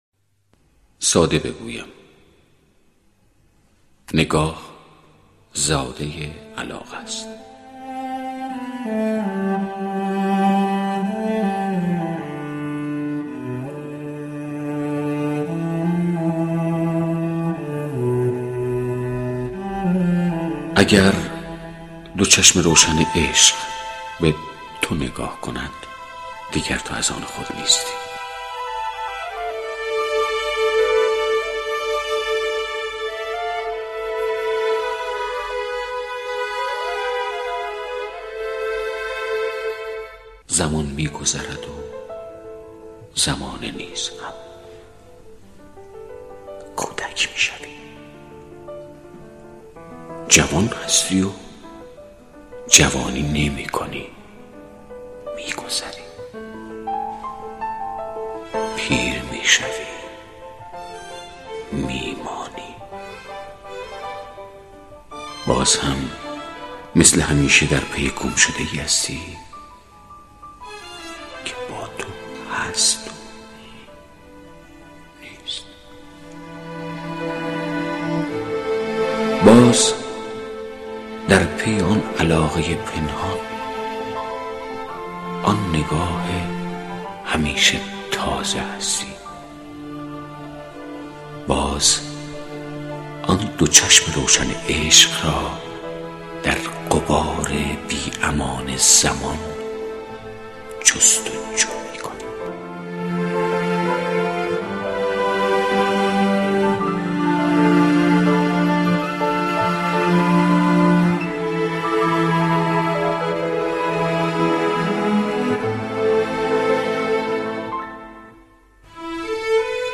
دانلود دکلمه ساده بگویم با صدای پرویز پرستویی با متن دکلمه
گوینده :   [پرویز پرستویی]